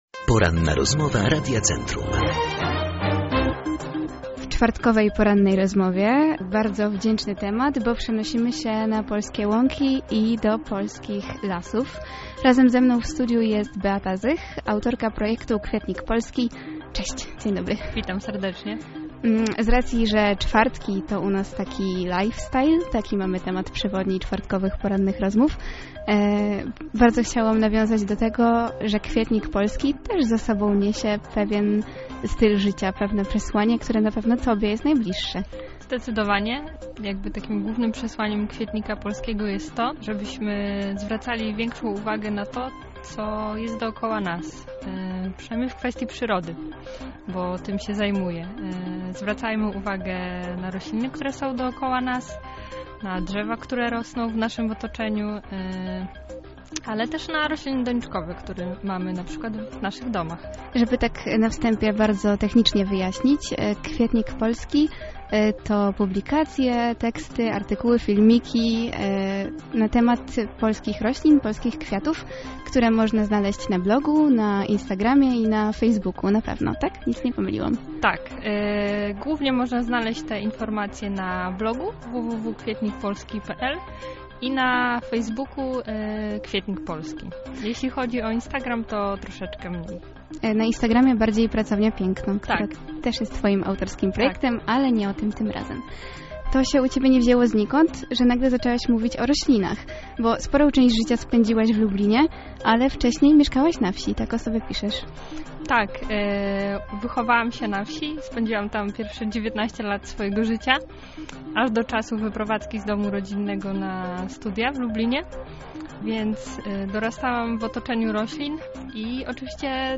rozmowa-zmniejszona-8-mb-.mp3